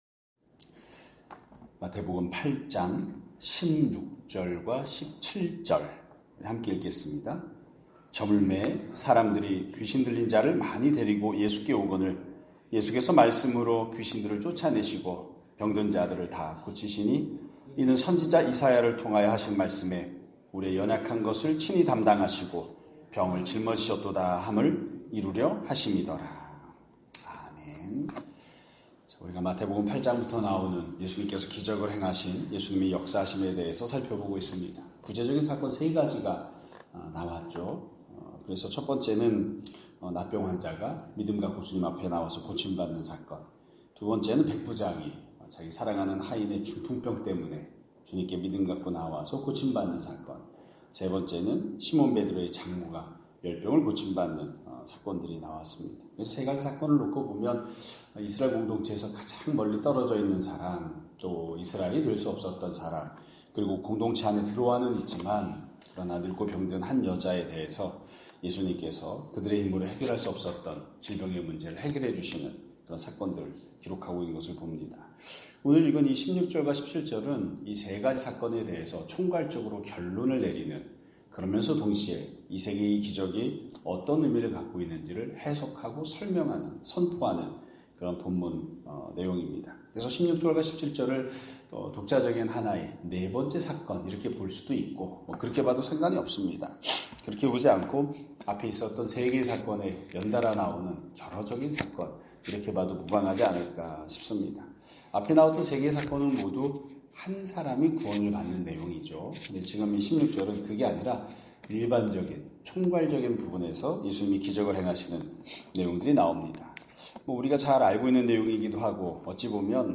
2025년 7월 11일(금요일) <아침예배> 설교입니다.